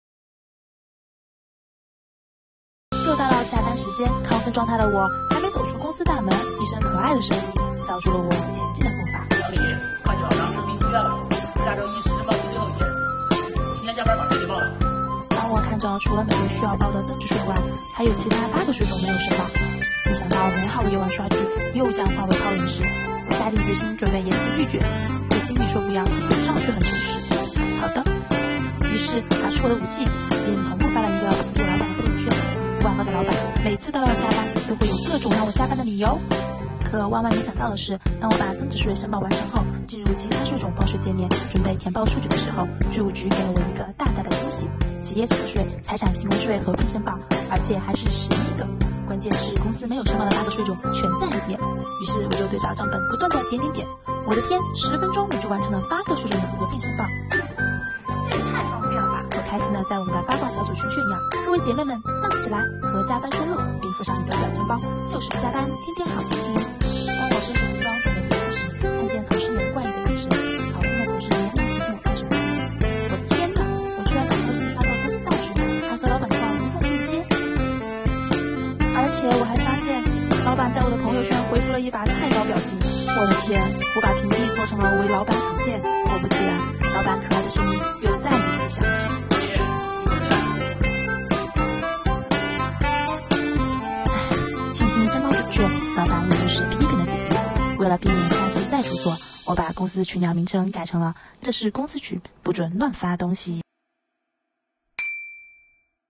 又到了周五下班时间，亢奋状态的小李还没走出公司大门，一声可爱的声音叫住了她前进的步伐！